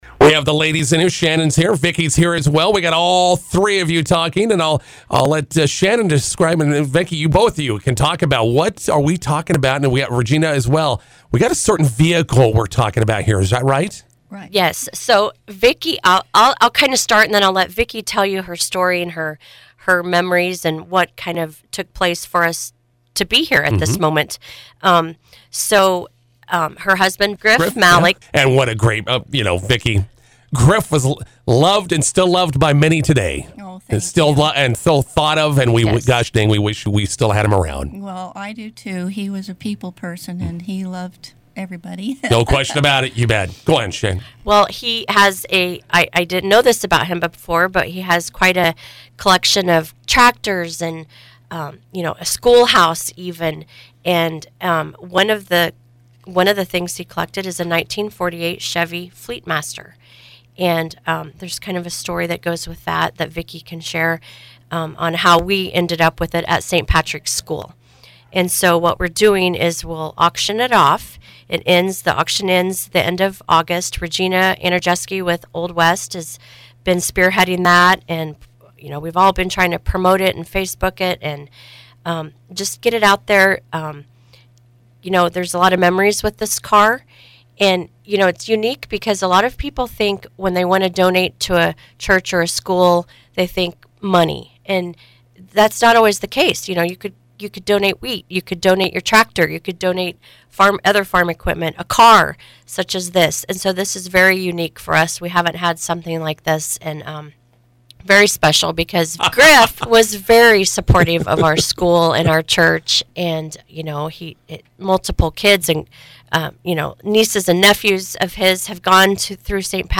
INTERVIEW: 1948 Chevrolet Fleetmaster Coupe online fundraiser continues.